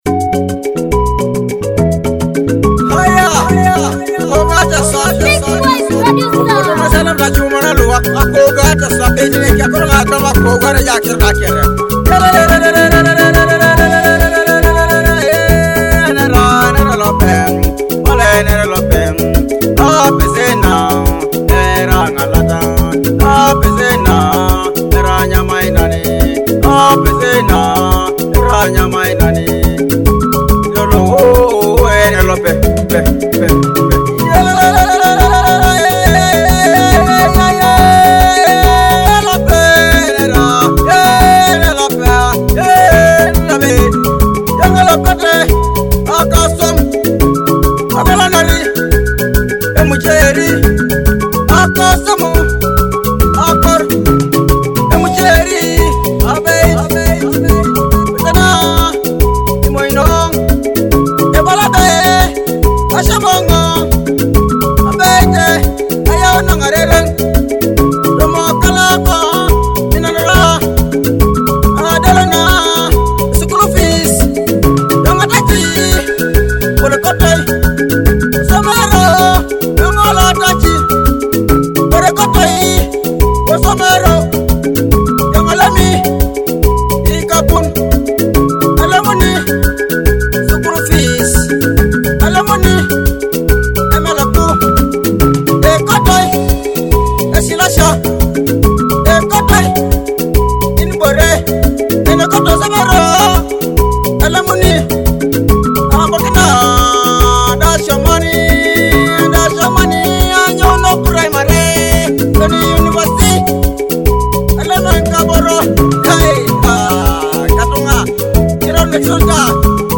traditional songs